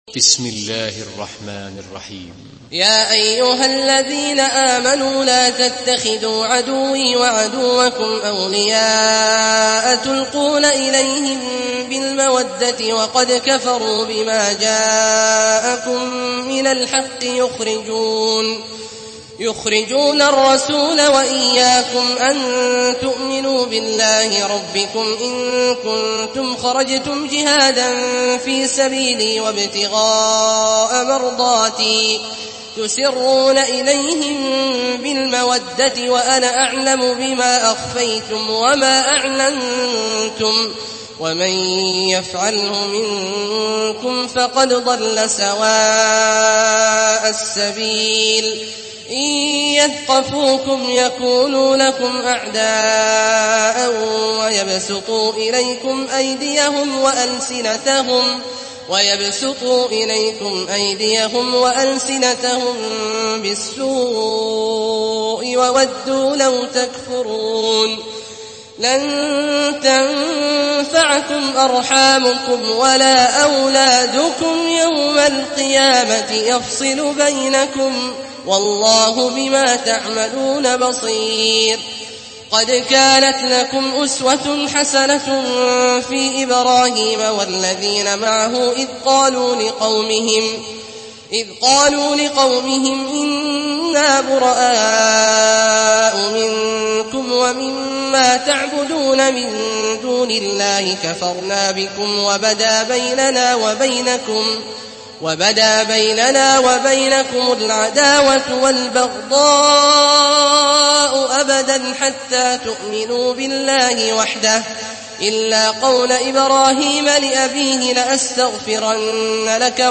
Surah الممتحنه MP3 in the Voice of عبد الله الجهني in حفص Narration
Listen and download the full recitation in MP3 format via direct and fast links in multiple qualities to your mobile phone.